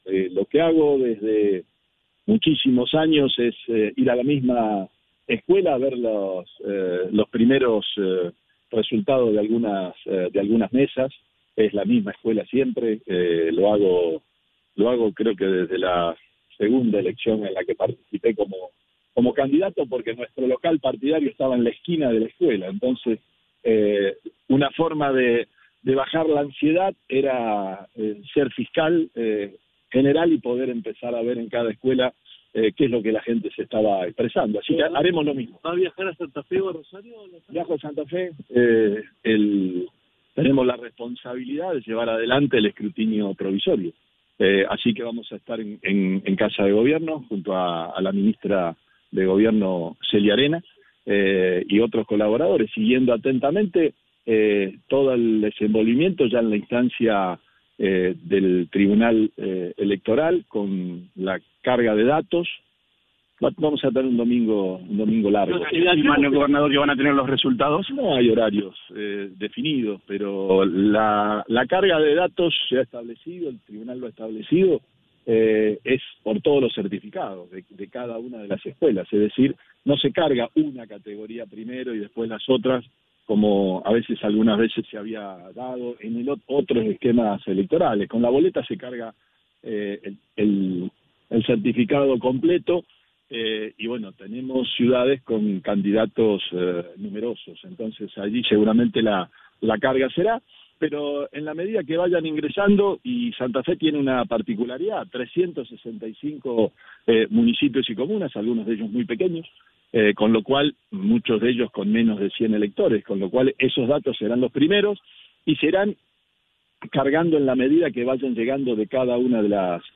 El gobernador Omar Perotti habló con Cadena 3 tras votar en Rafaela.